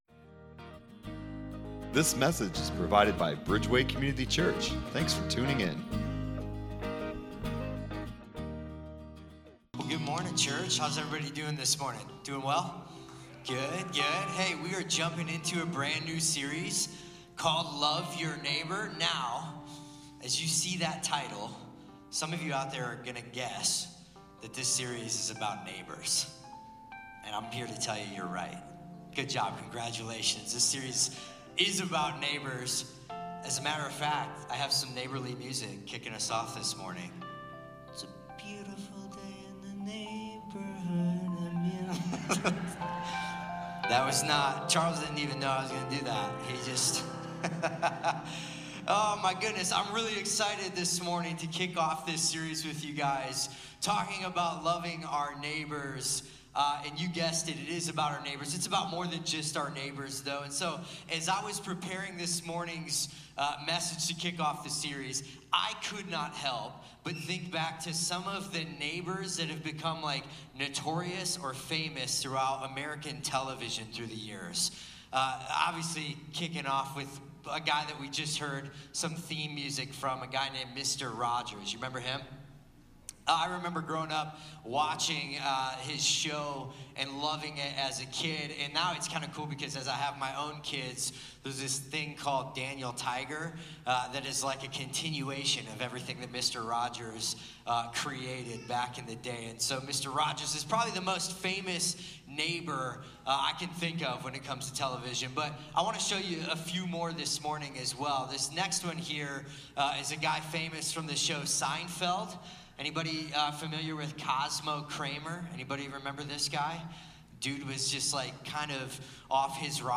Download Sermon Discussion Guide